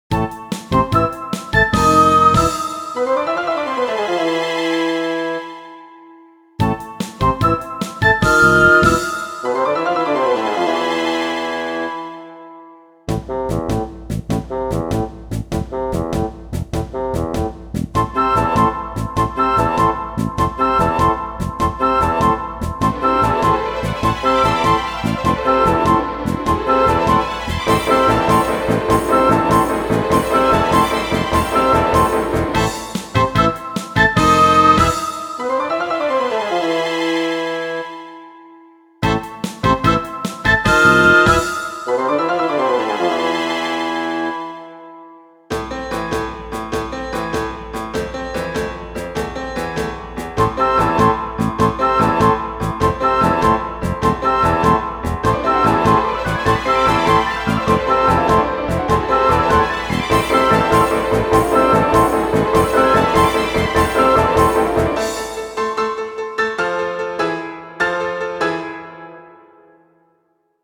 イメージ：キャラ コミカル   カテゴリ：RPG−イベント・その他